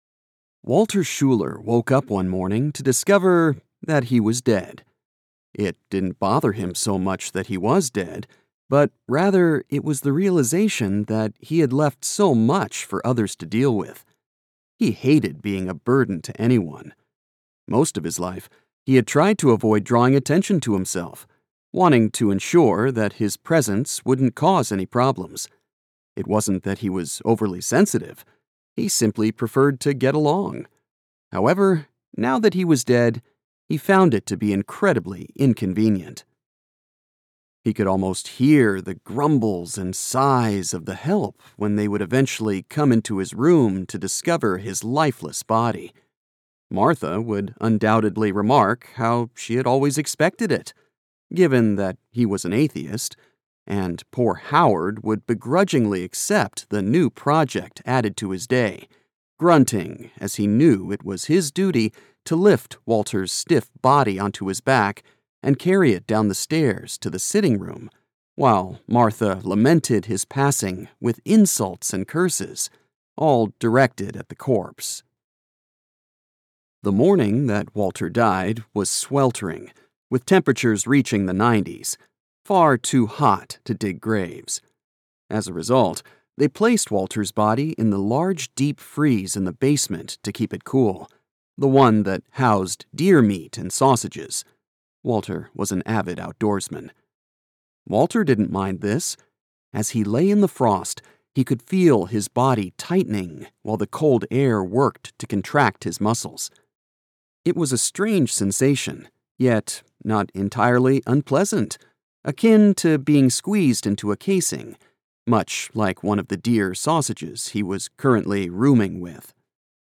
Audiobook Demo – Satire